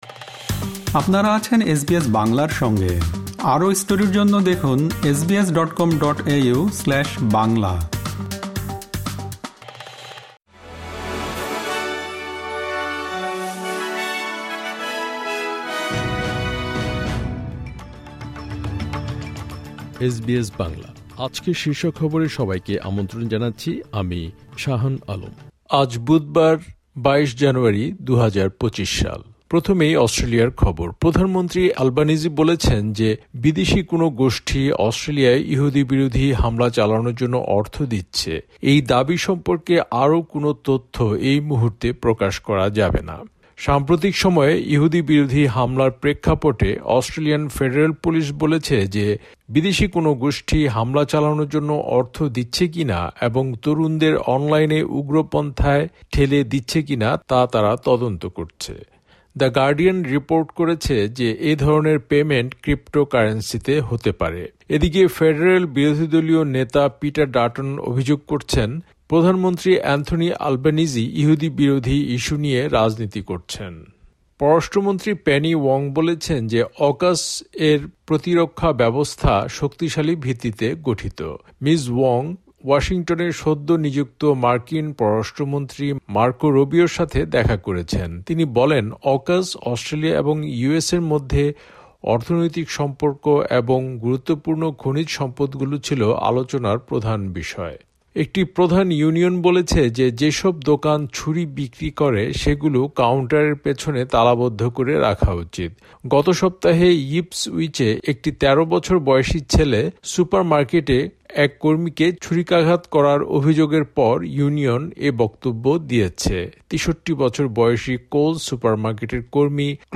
অস্ট্রেলিয়ার জাতীয় ও আন্তর্জাতিক সংবাদের জন্য আজকের এসবিএস বাংলা শীর্ষ খবর শুনতে উপরের অডিও-প্লেয়ারটিতে ক্লিক করুন।